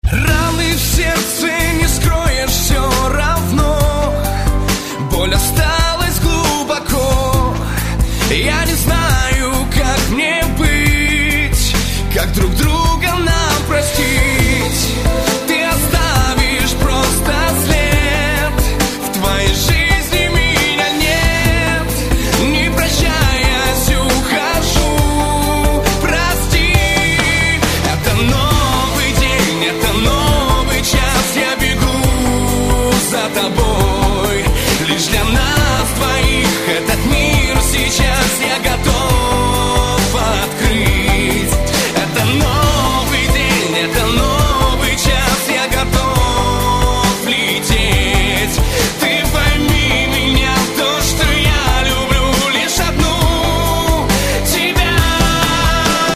Романтические
мужской вокал